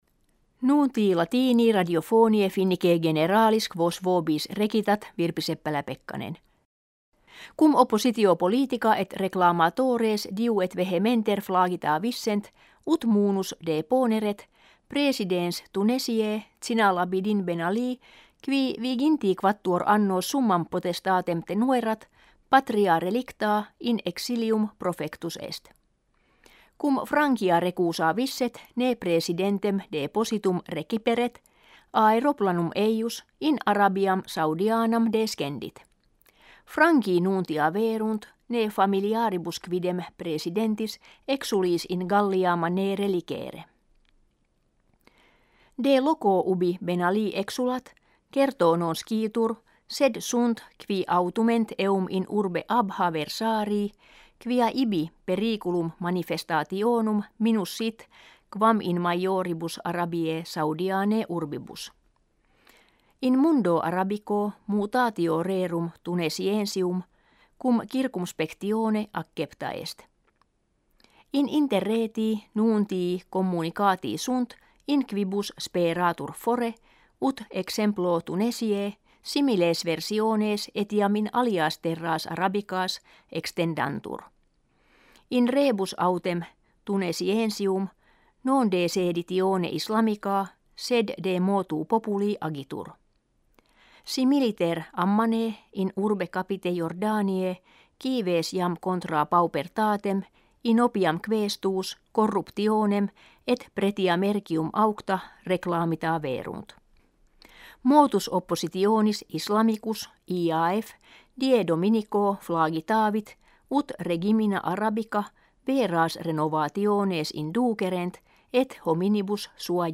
Финское радио на латыни (mp3)
finskoe_radio_na_latyni.mp3